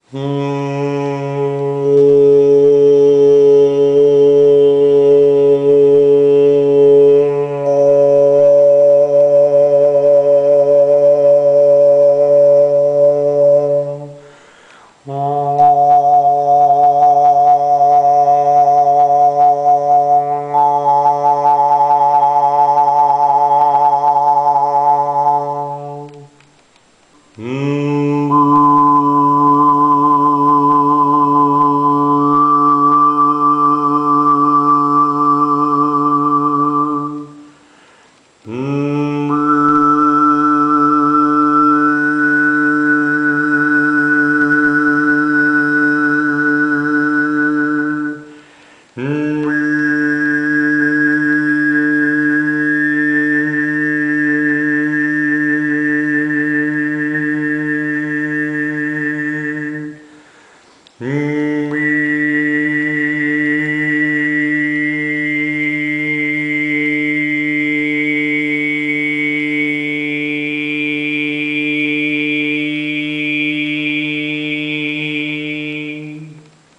Hörprobe Naturtonreihe Nr. 5 gesungen von H3 bis H16, mit Gong-Technik (H3-H6) und L-Technik (H7-H16)
Bei den hier zu hörenden Aufnahmen bleibt der Grundton jeweils stabil auf einer sogenannten Grundton-Frequenz
Das bedeutet, dass der Oberton H16 im Beispiel Nr. 5 genau vier Oktaven über dem Grundton erklingt!
naturtonreihe-h3-h16.mp3